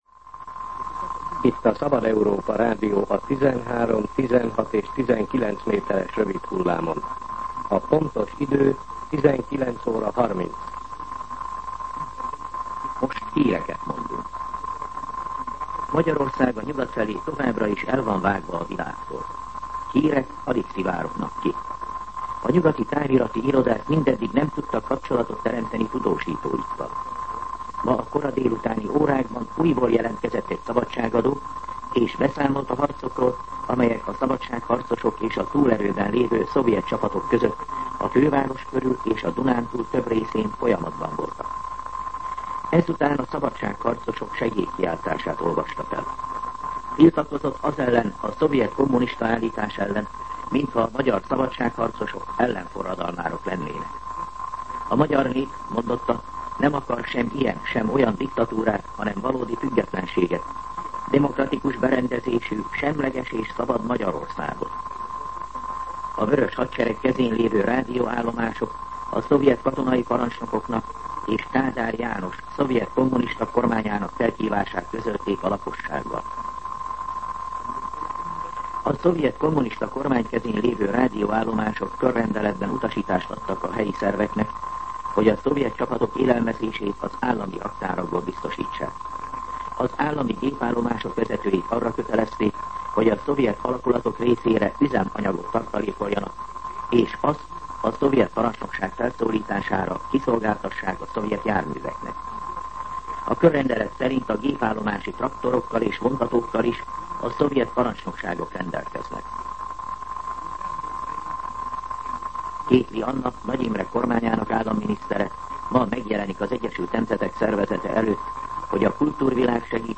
19:30 óra. Hírszolgálat